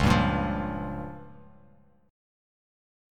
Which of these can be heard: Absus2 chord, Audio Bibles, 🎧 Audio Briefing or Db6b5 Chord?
Db6b5 Chord